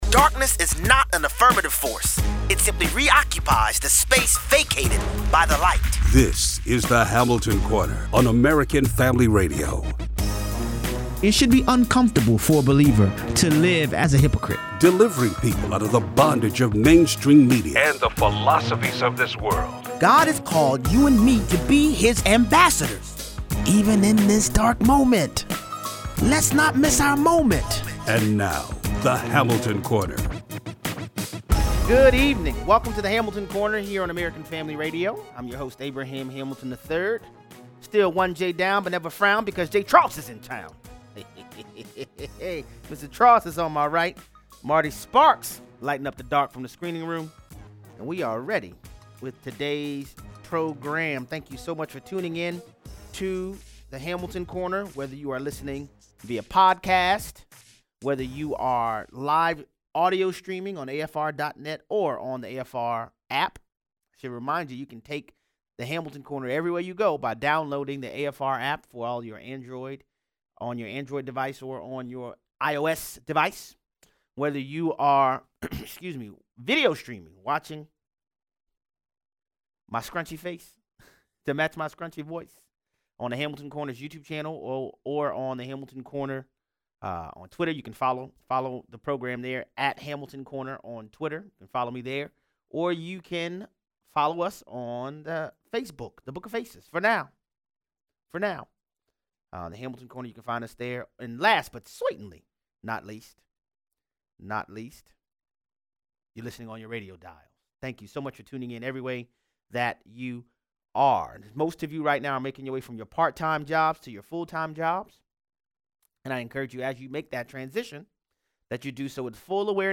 Callers weigh in.